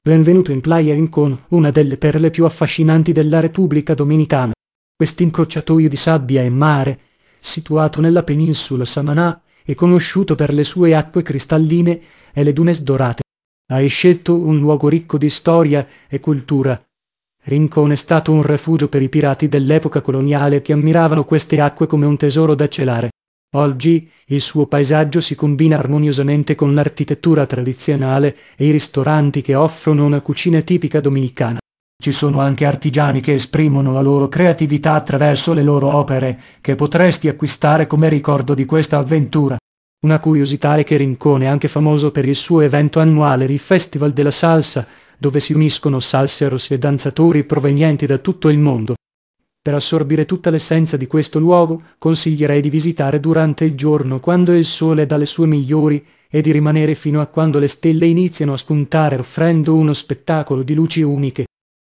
karibeo_api / tts / cache / 4856eb1dde688bd45456c8ab386e323e.wav